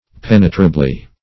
Pen"e*tra*bly, adv.